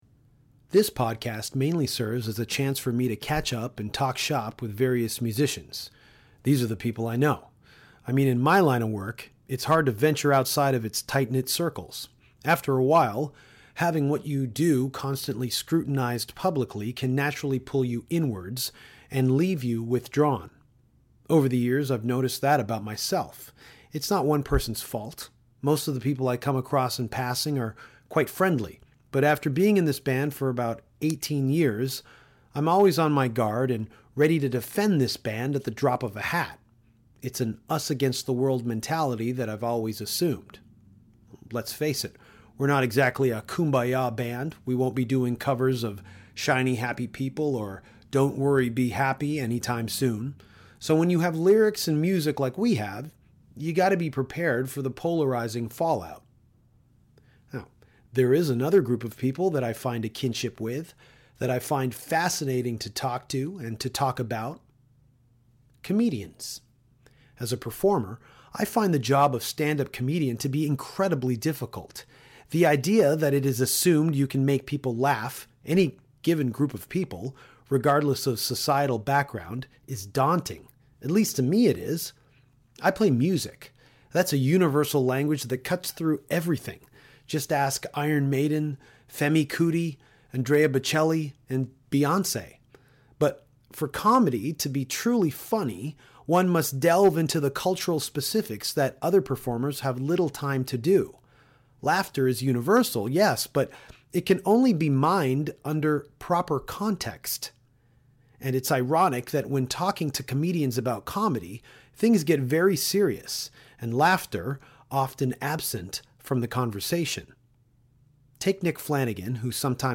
Another Motörboat podcast – this time Danko sits down with the great comedian, Big Jay Oakerson, to talk comedy, performance and Andrew Dice Clay.